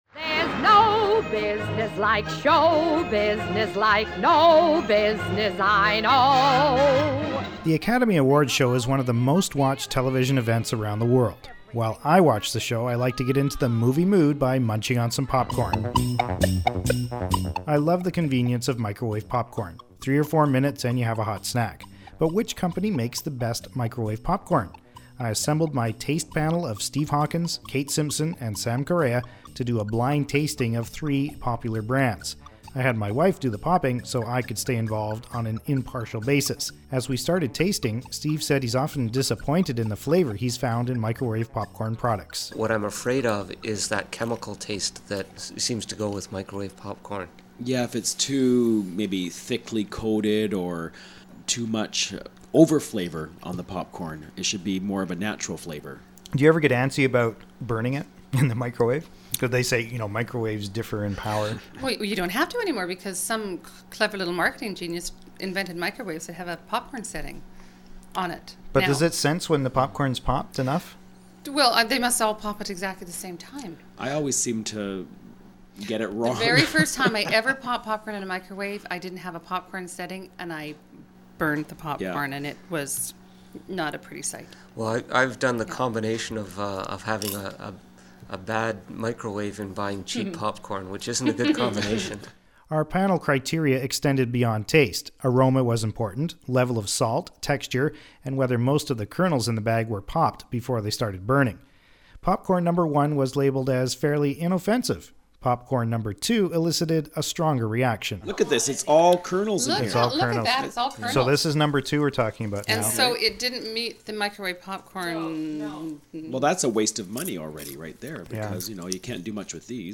If you want to listen to their comments, click